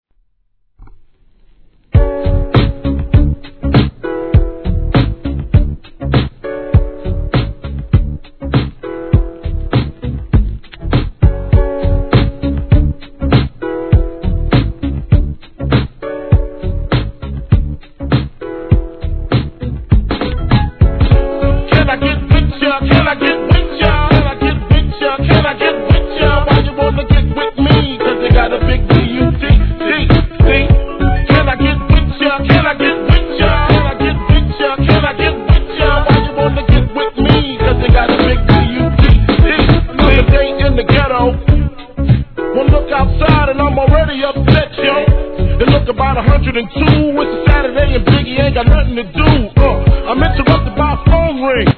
1. HIP HOP/R&B
音質もバッチリです♪